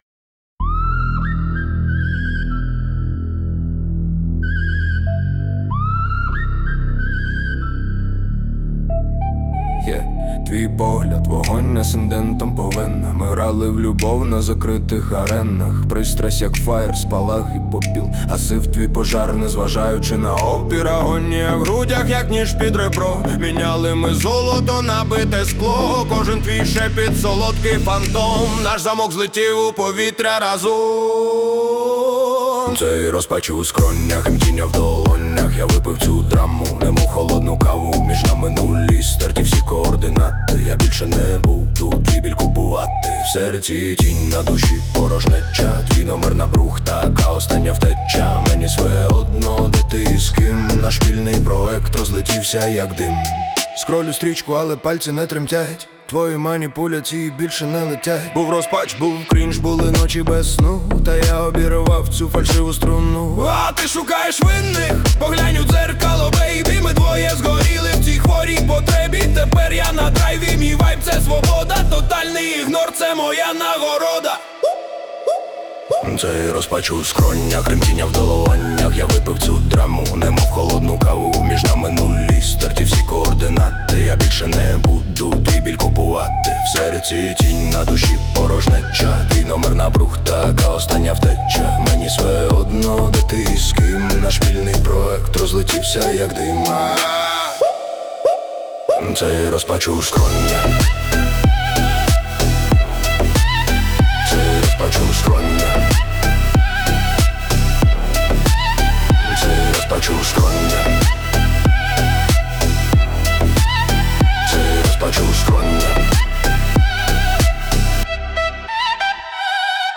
Сучасний стильний український трек